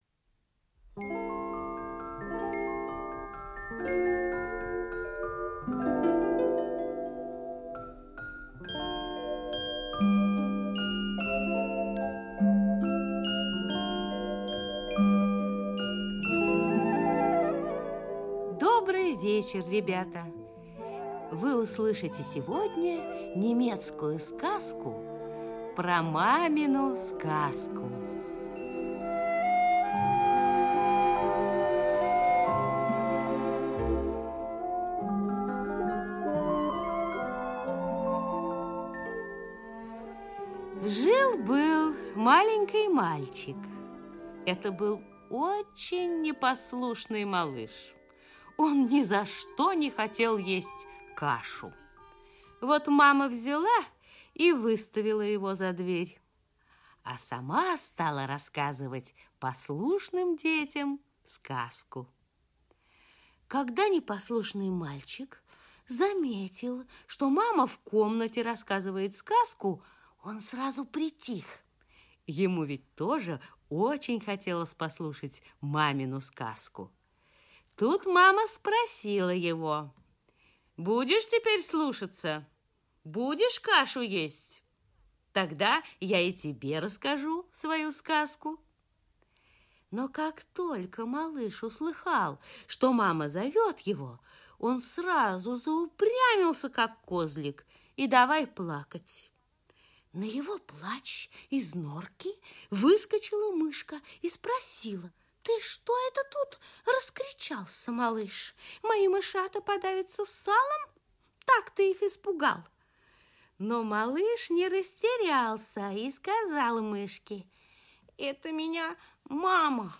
Про мамину сказку - аудиосказка Фаллада - слушать онлайн | Мишкины книжки